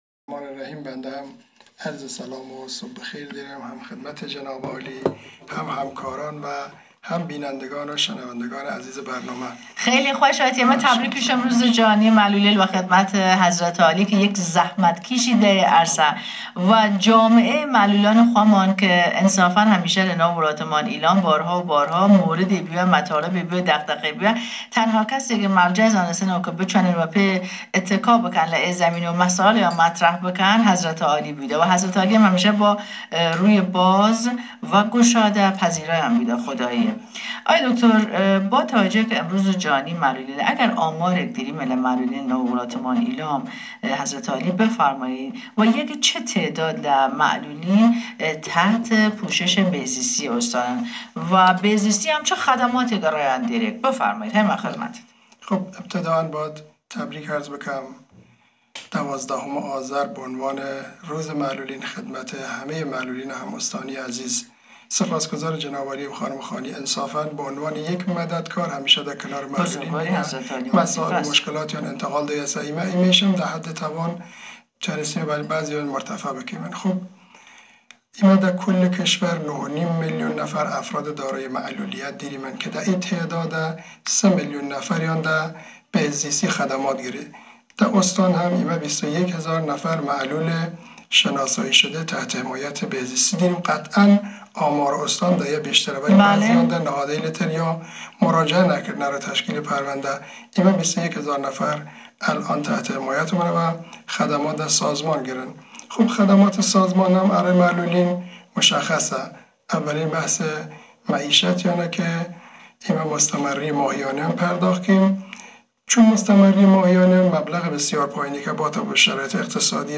صوت| مصاحبه رادیویی مدیرکل بهزیستی با برنامه "شوکیانه"